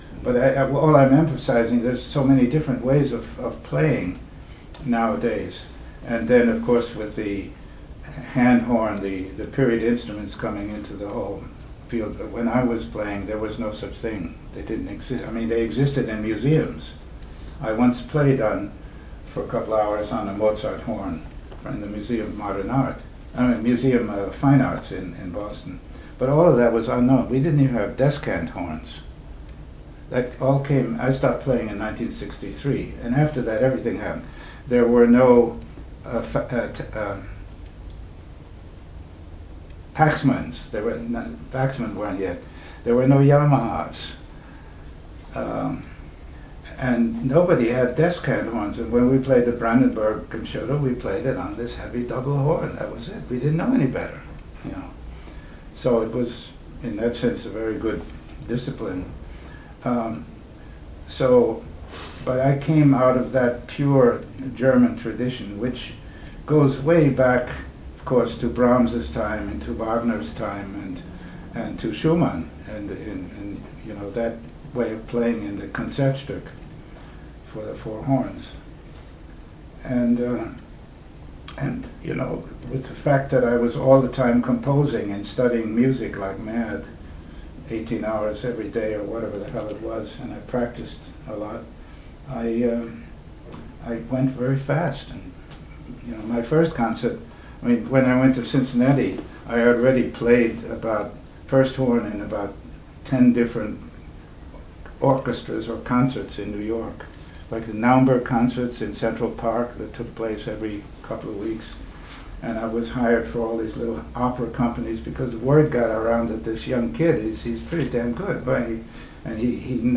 Next, I have some brief excerpts recorded from lectures he gave during his residency at UW-Madison (mentioned in the above quote) in fall 2005.
• “Musical Beginnings,” UW-Madison Horn Studio Class, September 23, 2005